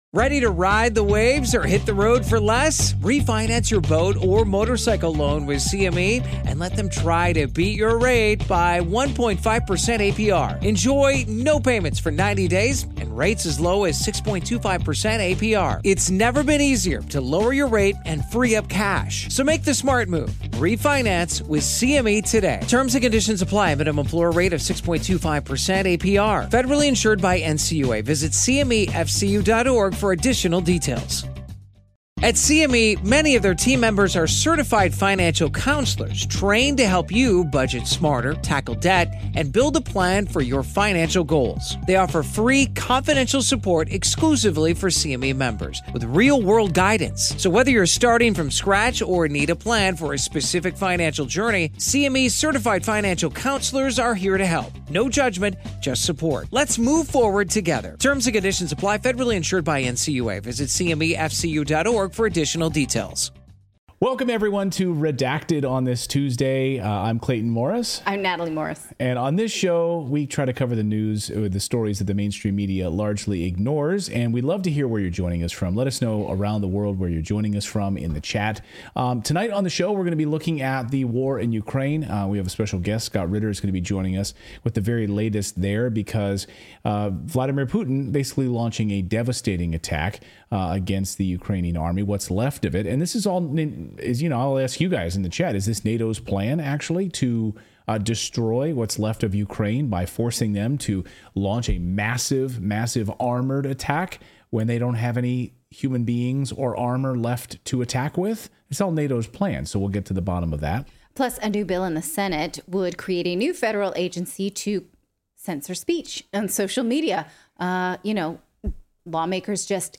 NATO has been pushing Ukraine to launch massive armored offensives instead of small scale pin prick attacks. This plan has been devastating to the Ukrainian army which has come under intense air and ground attacks by the Russian army. Is it NATO's plan to finish off what's left of Ukraine's military? Former U.N. weapons inspector Scott Ritter joins us for tonight's live show.